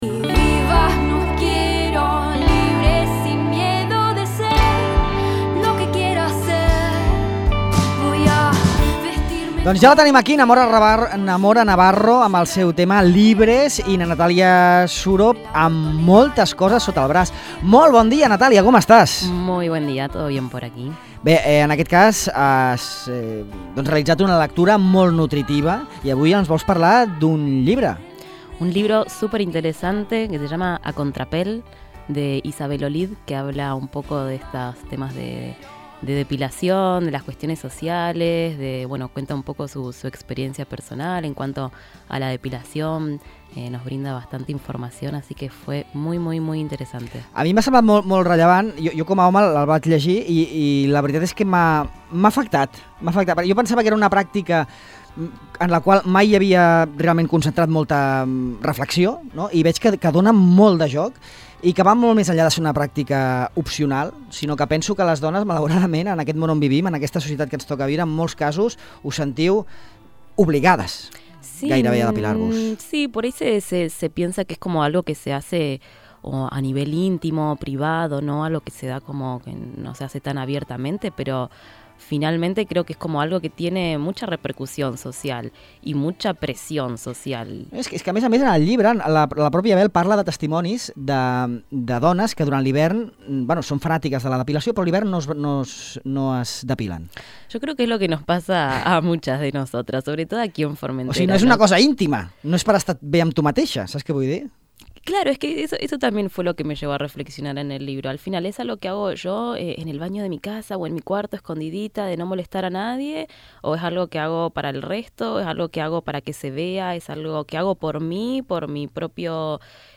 entrevista a Bel Olid, escriptora, traductora i una de les referents del pensament feminista